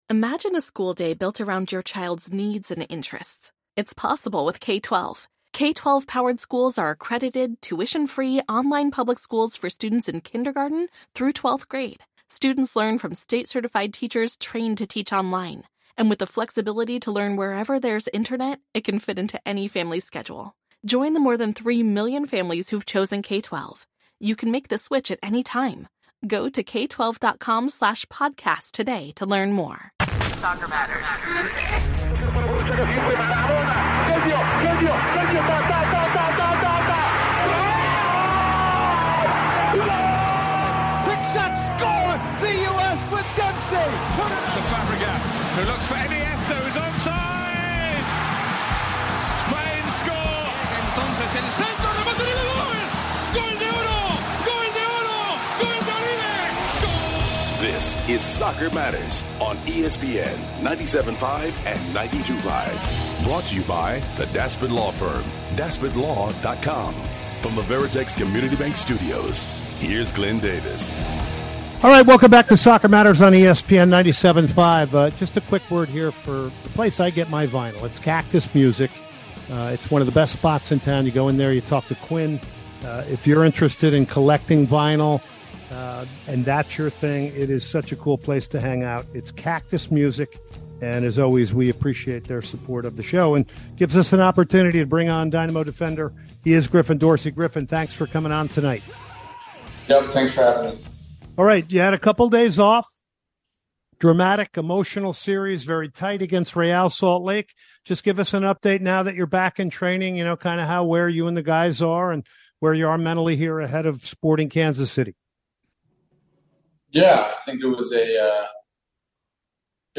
three interviews.